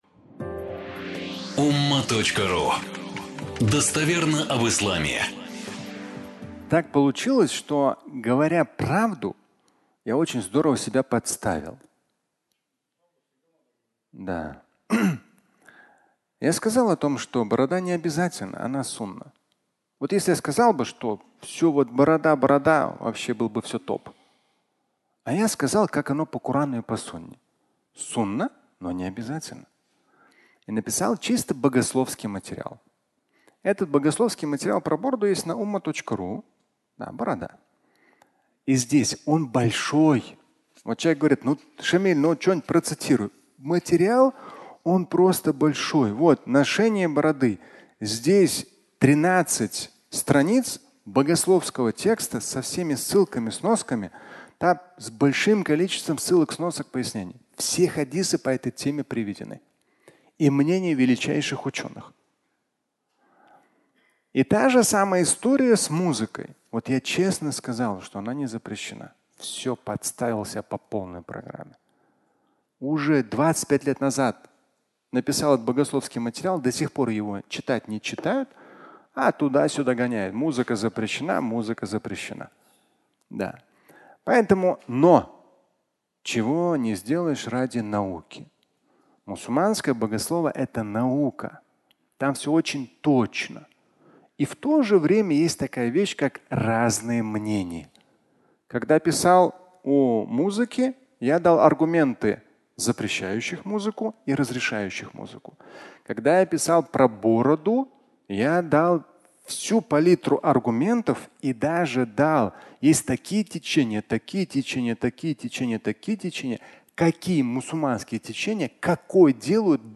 Красивая борода (аудиолекция)